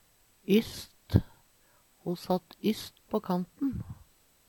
Tilleggsopplysningar nokon seier og ytst Sjå òg innst (Veggli) Høyr på uttala Ordklasse: Preposisjon Attende til søk